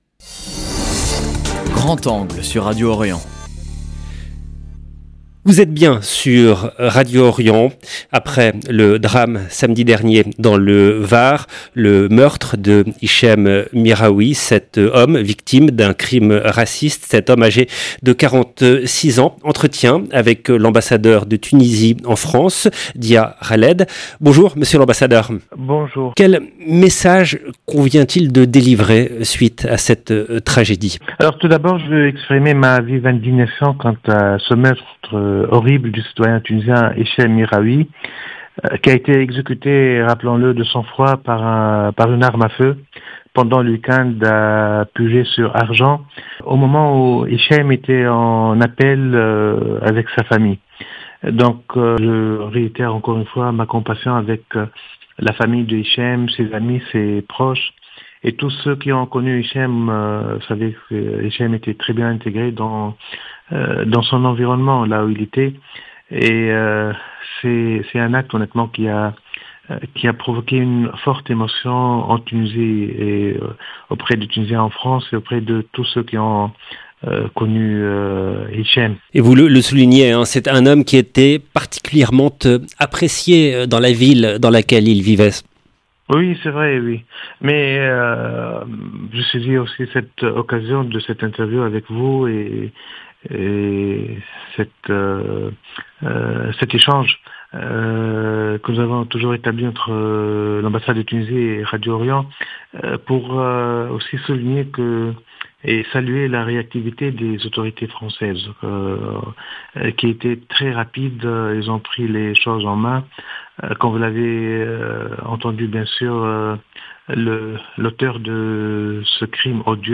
Crime raciste en France : entretien avec l’ambassadeur de Tunisie en France